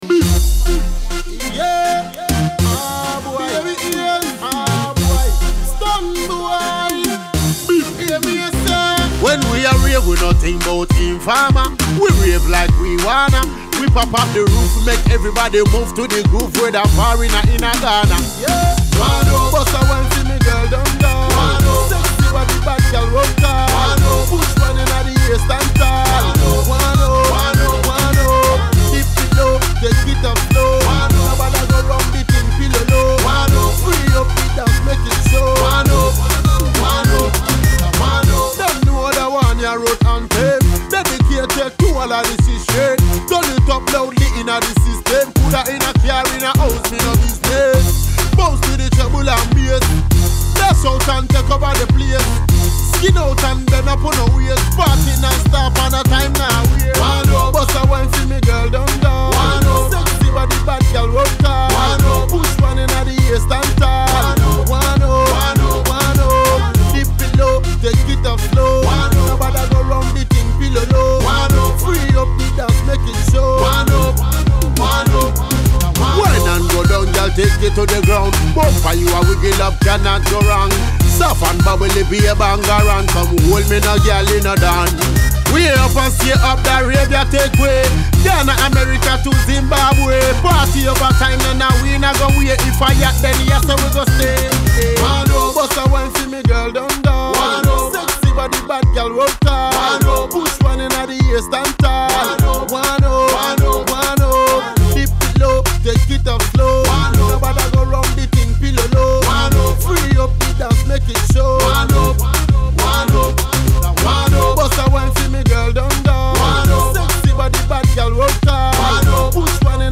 Ugandan Afrobeats an EDM